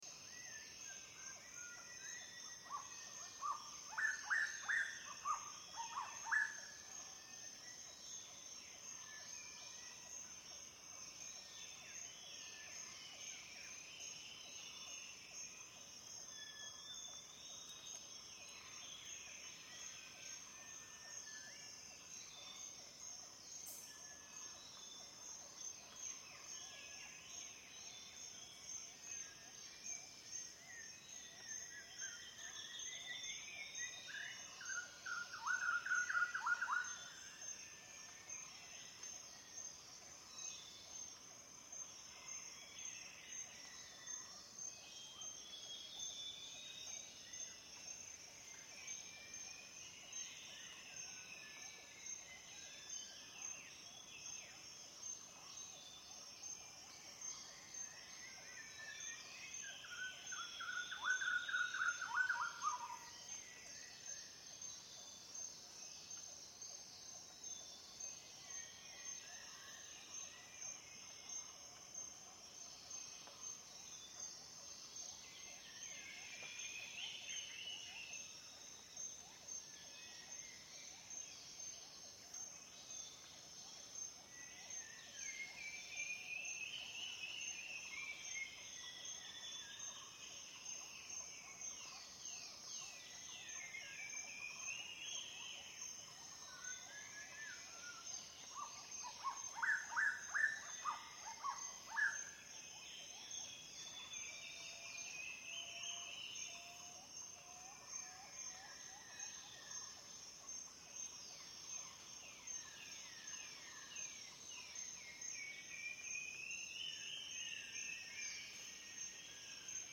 Звуки утра
Шепот утреннего леса